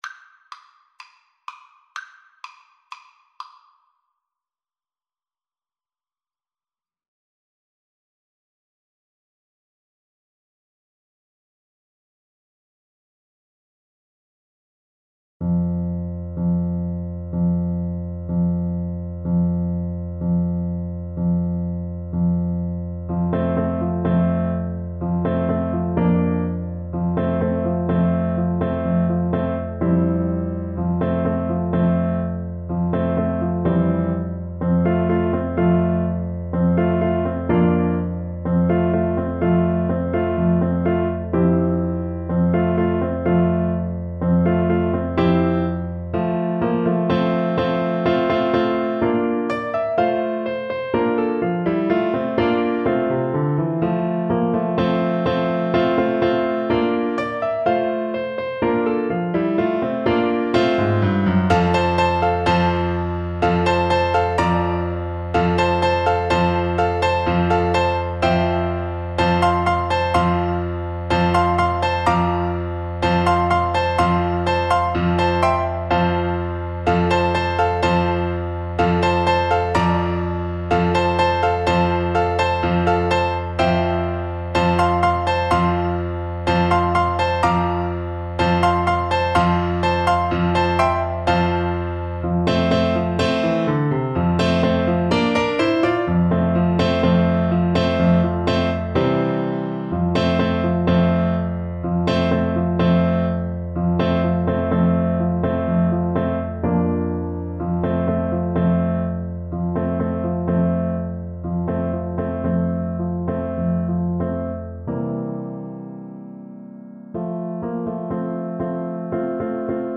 Traditional Trad. Cotton-Eyed Joe Trumpet version
Play (or use space bar on your keyboard) Pause Music Playalong - Piano Accompaniment transpose reset tempo print settings full screen
Trumpet
Traditional Music of unknown author.
2/2 (View more 2/2 Music)
F major (Sounding Pitch) G major (Trumpet in Bb) (View more F major Music for Trumpet )
Allegro Energico = c.88 (View more music marked Allegro)